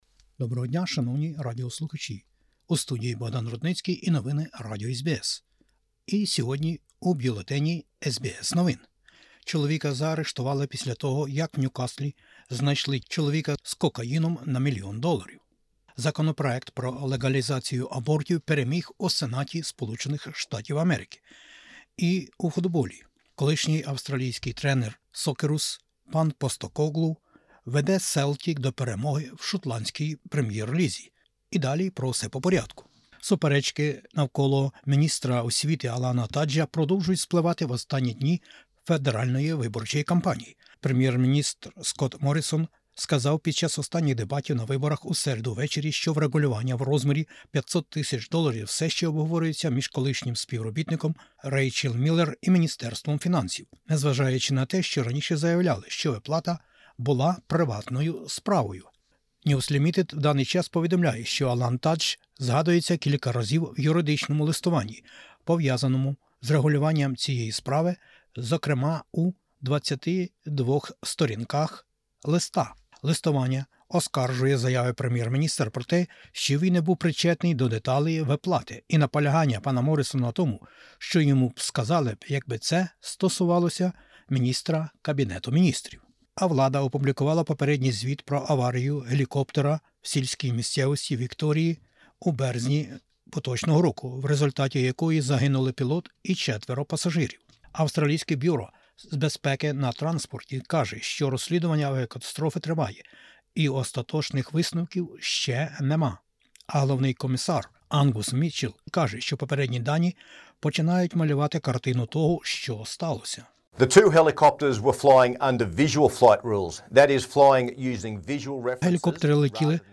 SBS новини українською - 12/05/2022